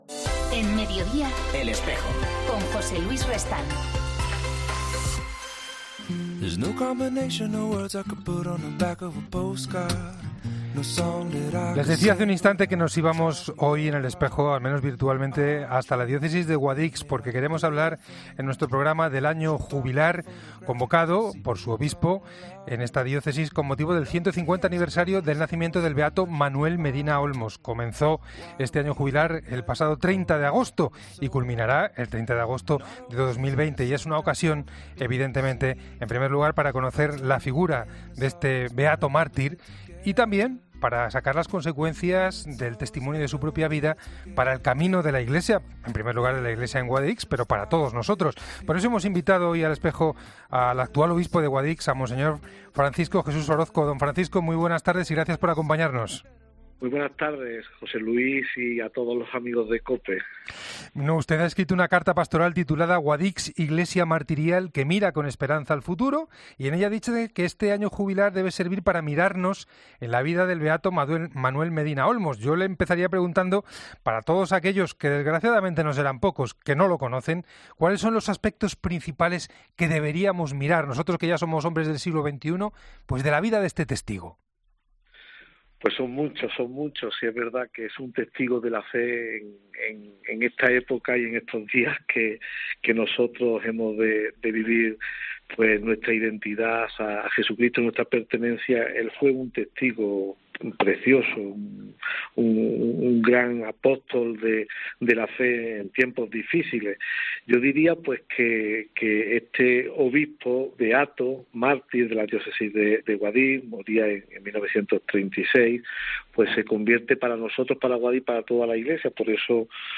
Francisco Orozco, obispo de la Diócesis de Guadix, presenta al beato Manuel Medina Olmos, que padeció el martirio durante la persecución religiosa de 1936.